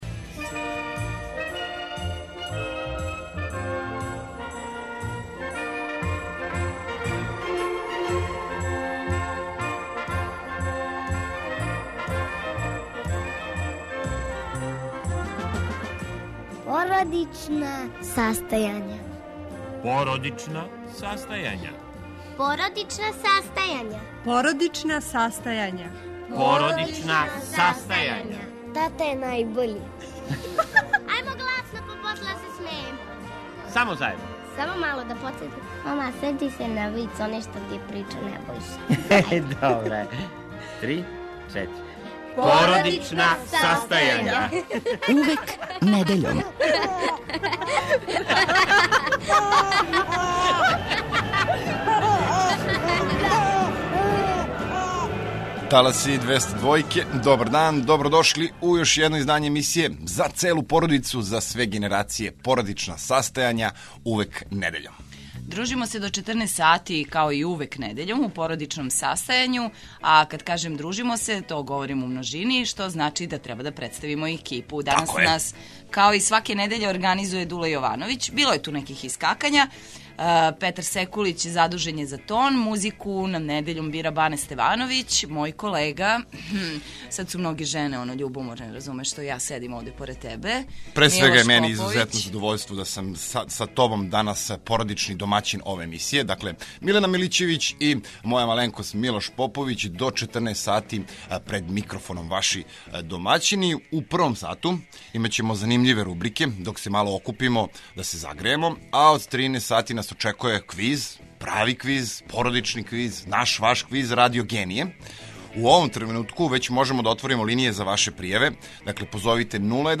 Емисија за целу породицу